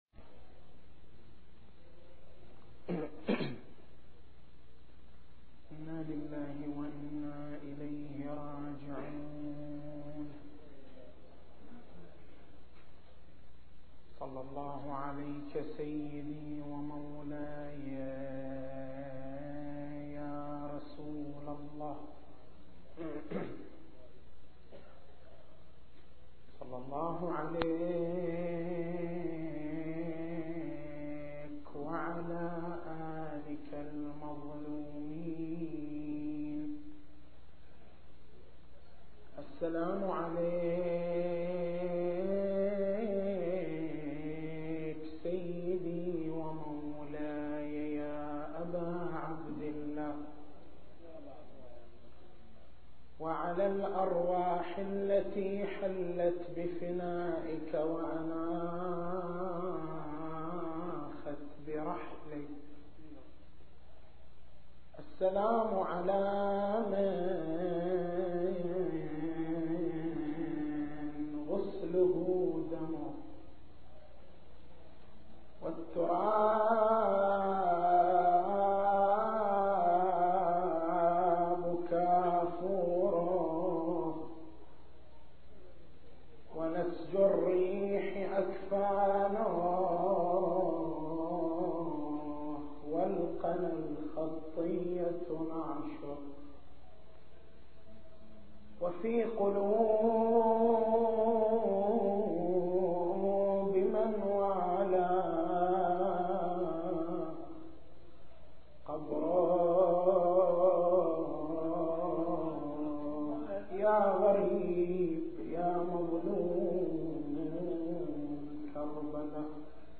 تاريخ المحاضرة: 13/01/1424 نقاط البحث: عمق مبدأ التوحيد في الفكر الشيعي انسجام عقائد الشيعة مع مبدأ التوحيد التسجيل الصوتي: تحميل التسجيل الصوتي: شبكة الضياء > مكتبة المحاضرات > محرم الحرام > محرم الحرام 1424